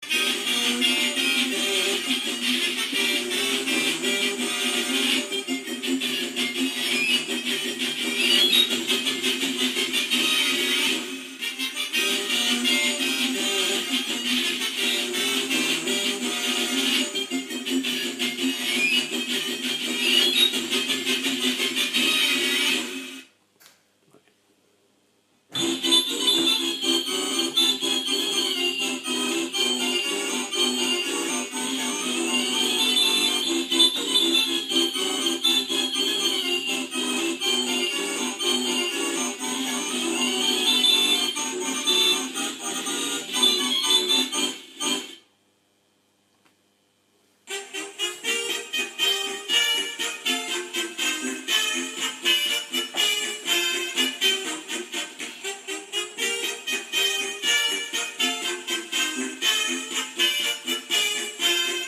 Zirkus - Sound für LGB 41360-E035
Soundgeräusch